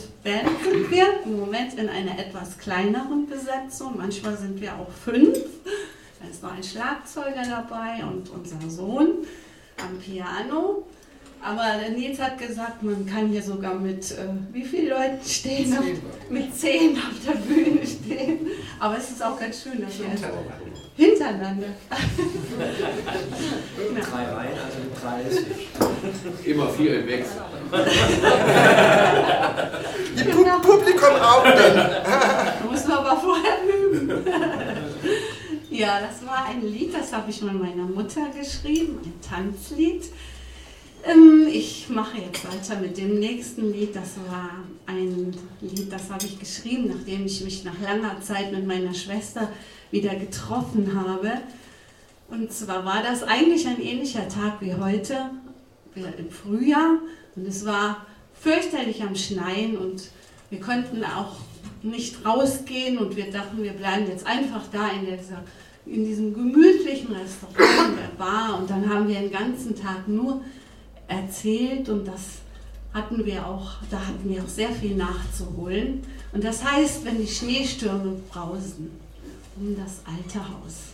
Konzert Liedermacher im Schwarzen Kopf (Saarburg)
02 - Ansage.mp3